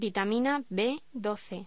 Locución: Vitamina B12
voz